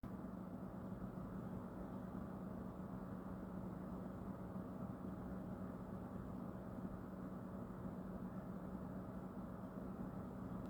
Damit meine ich, dass die Lüfter (nicht die der Grafikkarte) nur auf dem Desktop, also ohne Beanspruchung durch Tasks, etc..., schon sehr laut sind.
Das hört dann auch nicht mehr auf, also die Lüfter werden nicht mehr leiser, sondern bleiben so lange in diesem Zustand, bis der PC dann irgendwann abstürzt.
PC [laut].m4a